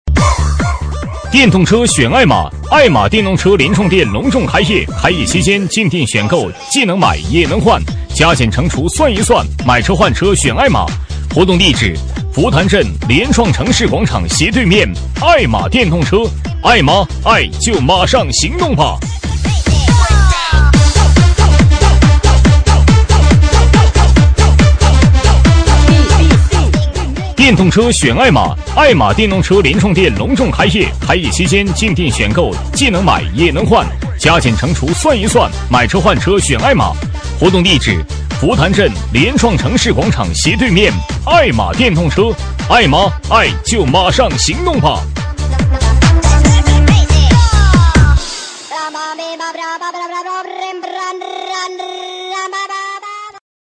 【男8号促销】爱玛电动车
【男8号促销】爱玛电动车.mp3